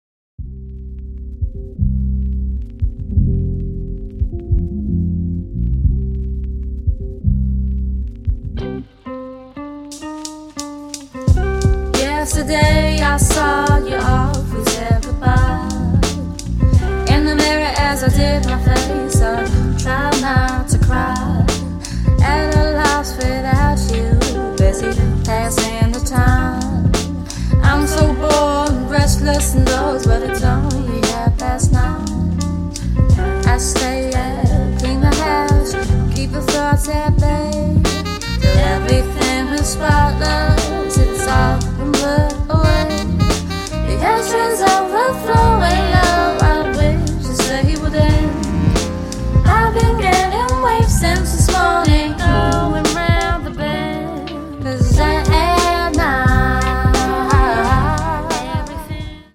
Super downtempo soul